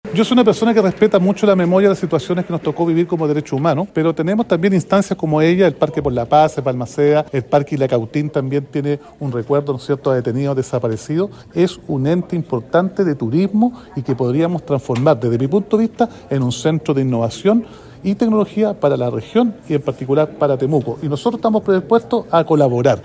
Sin embargo, la idea fue desestimada por el alcalde de Temuco, Roberto Neira, que propuso transformar el espacio en un centro tecnológico con miras al futuro.